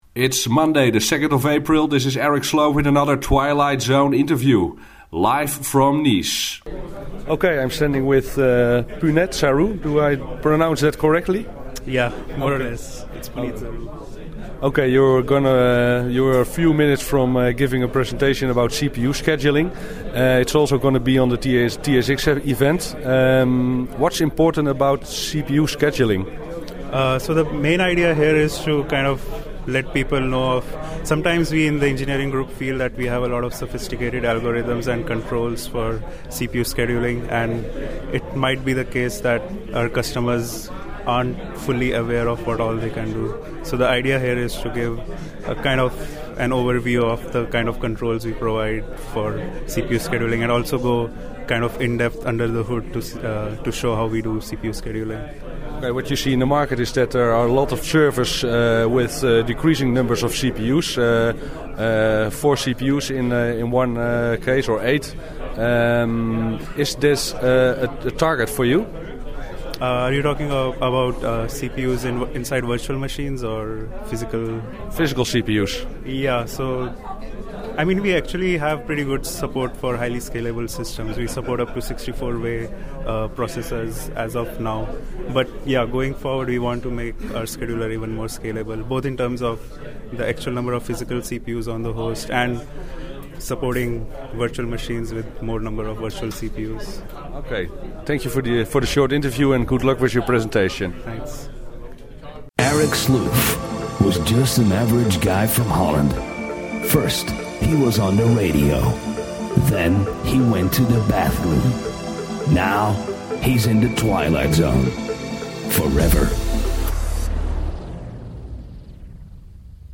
TSX Interview